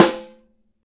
SNR 4-WAY 04.wav